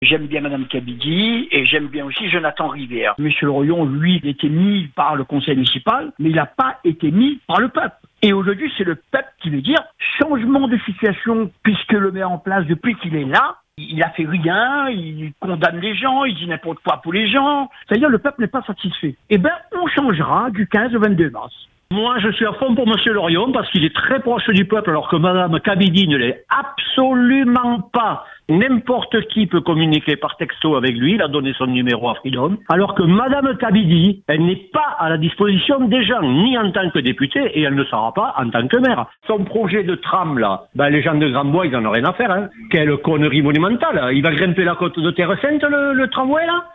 Deux auditeurs de la commune ont ainsi déjà annoncé leur choix : l’un se dit partisan d’Emeline K/Bidi, l’autre affiche son soutien à David Lorion.